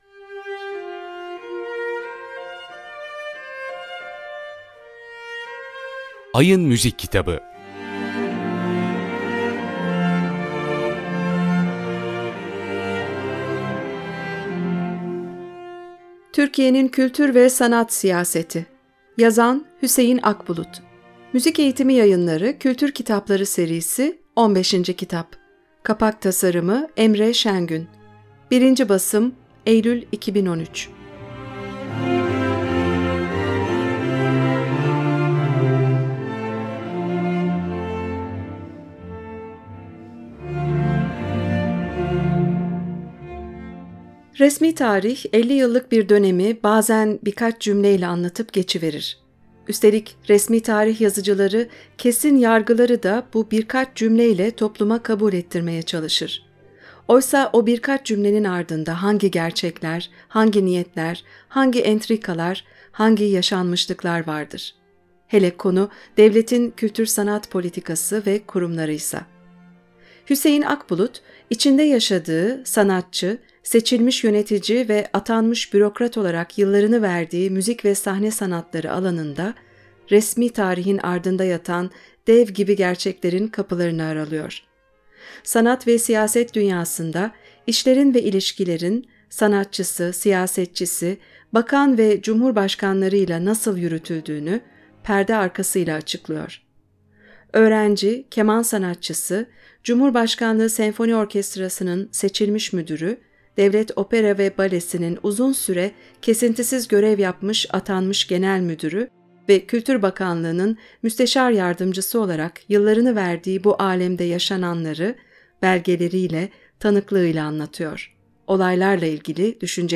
Borusan Klasik Müzik Radyosu Kitap Tanıtım (Nisan 2023)